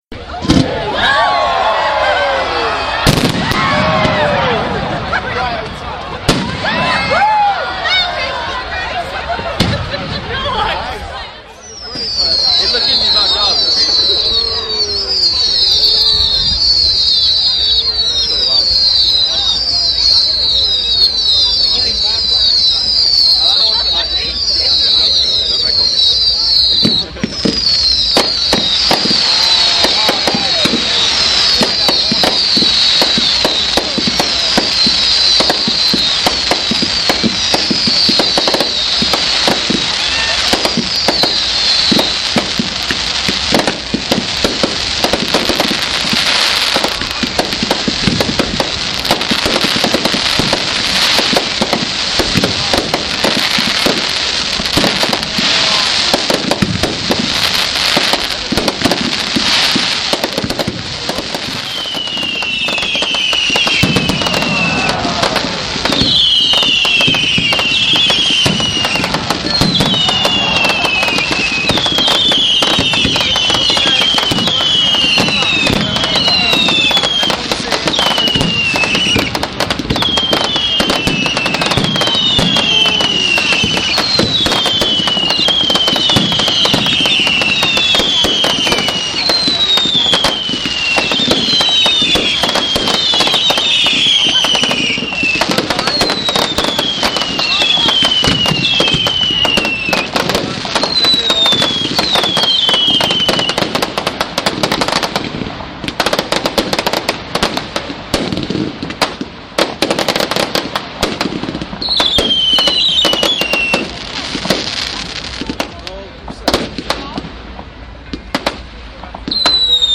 Bonfire Night 2011: Firework display